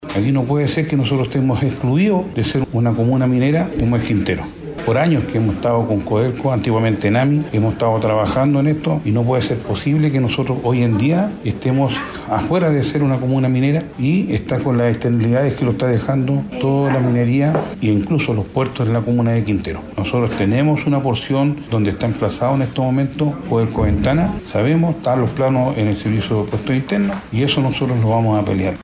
En este sentido, el alcalde de Quintero, Rolando Silva, indicó que no puede ser posible que la comuna esté apartada de ser una comuna minera.